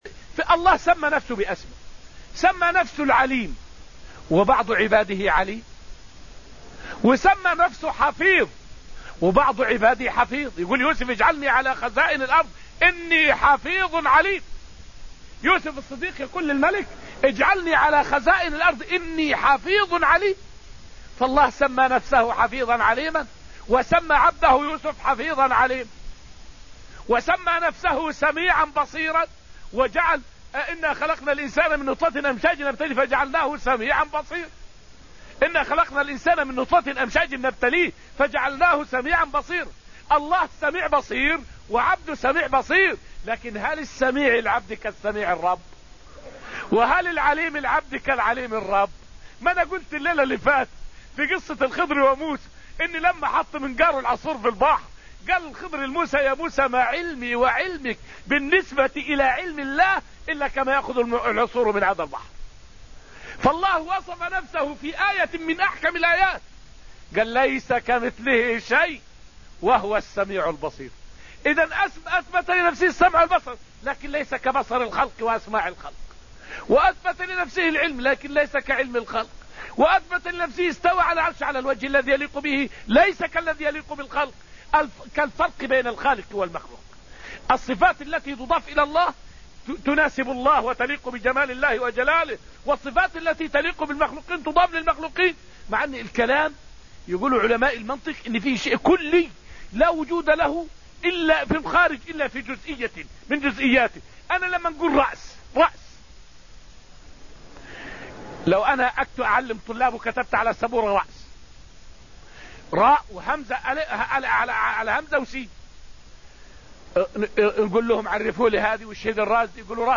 فائدة من الدرس الثالث من دروس تفسير سورة الحديد والتي ألقيت في المسجد النبوي الشريف حول أن صفات الله ليست كصفات الخلق.